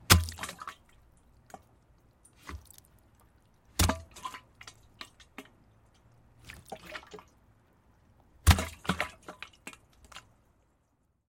随机 "塑料气体容器的进气管尴尬地进入，并在里面撞来撞去
描述：塑料气体容器进料软管笨拙地进入内部
Tag: 笨拙 塑料 里面 气体 左右 容器 软管 进料